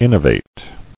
[in·no·vate]